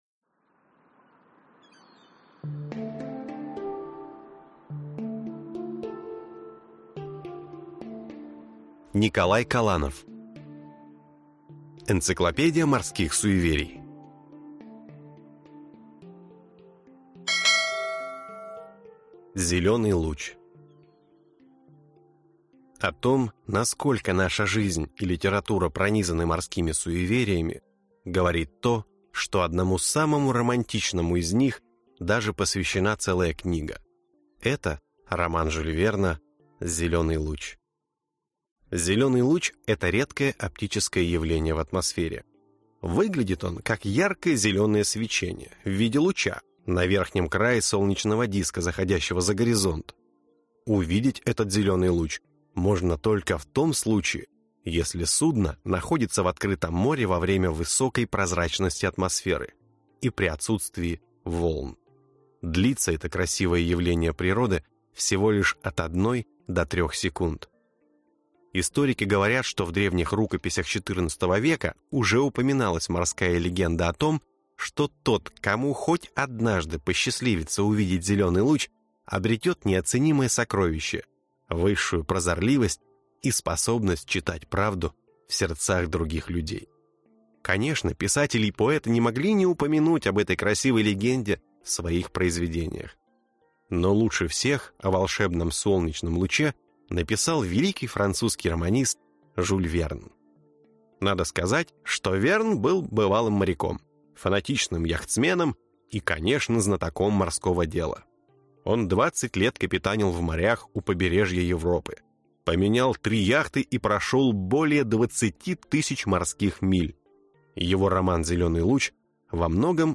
Аудиокнига Энциклопедия морских суеверий. Часть 3 | Библиотека аудиокниг